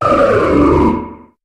Cri de Froussardine dans sa forme Banc dans Pokémon HOME.